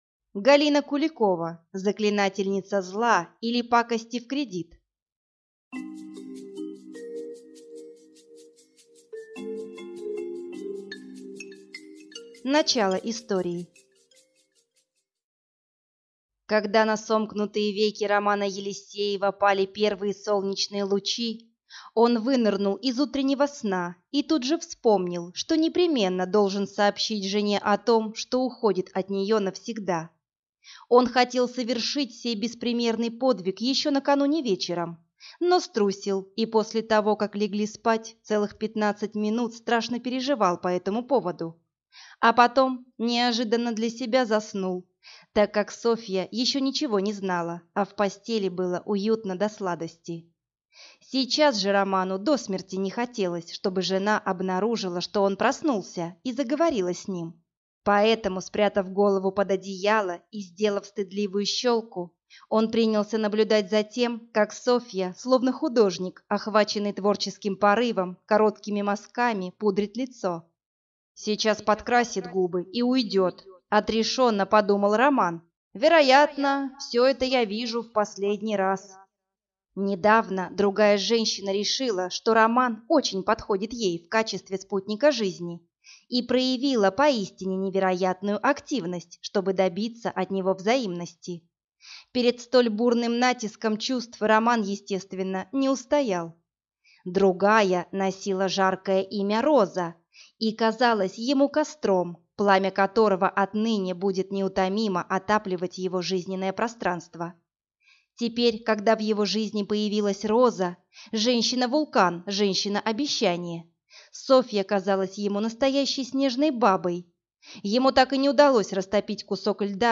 Аудиокнига Заклинательница зла, или Пакости в кредит | Библиотека аудиокниг